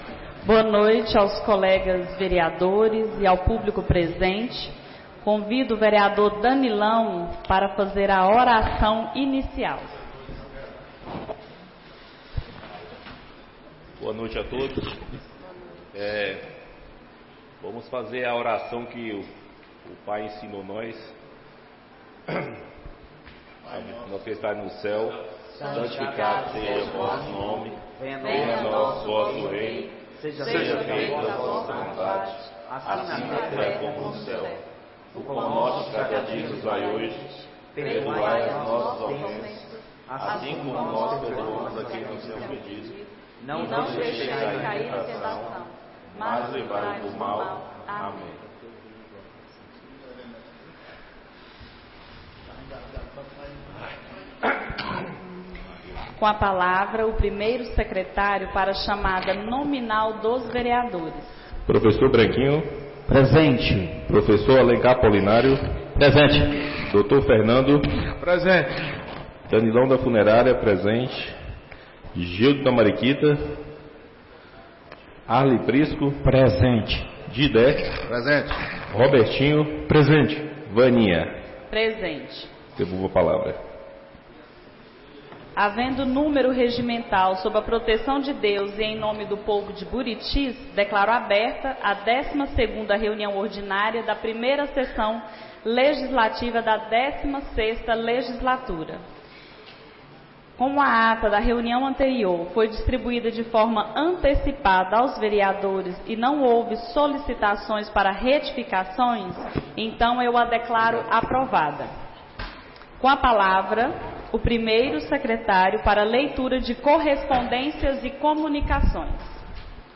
12ª Reunião Ordinária da 1ª Sessão Legislativa da 16ª Legislatura - 07-04-25